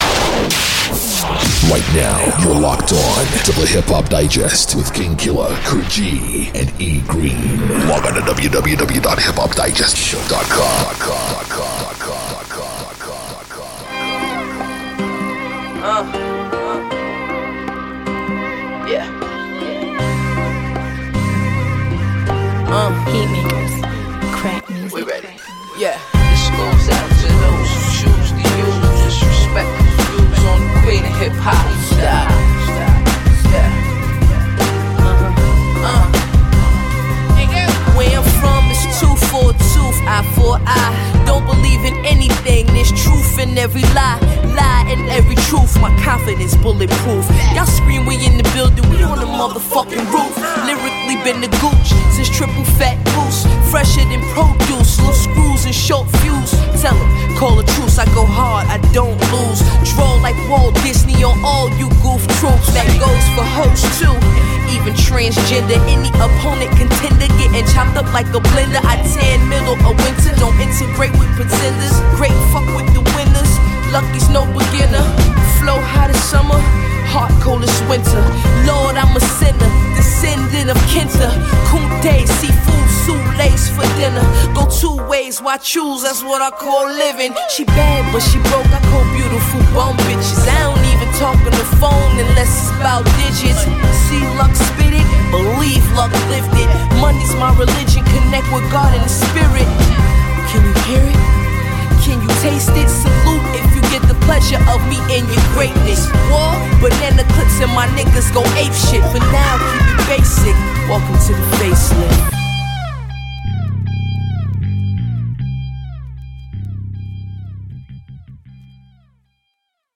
Hip Hop Digest Bonus Music Show!